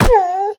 Minecraft Version Minecraft Version 1.21.5 Latest Release | Latest Snapshot 1.21.5 / assets / minecraft / sounds / mob / wolf / sad / hurt2.ogg Compare With Compare With Latest Release | Latest Snapshot
hurt2.ogg